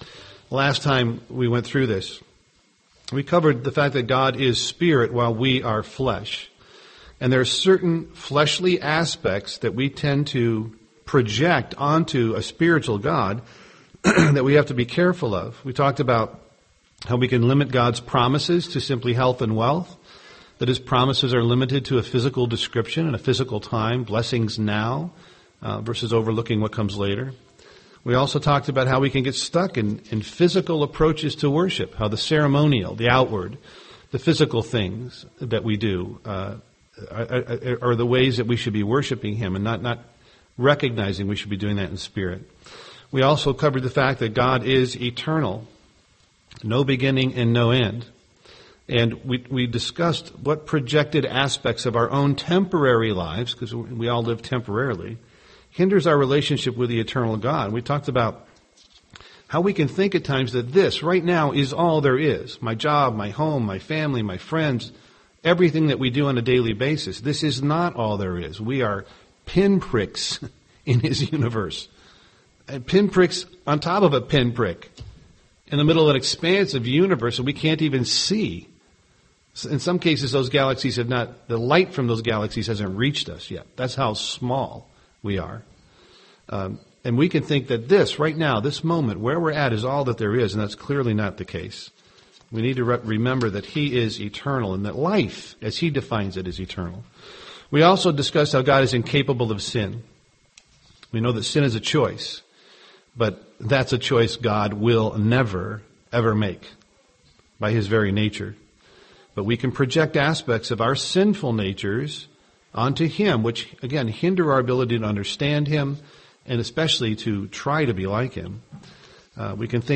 Given in Twin Cities, MN
UCG Sermon image of god Studying the bible?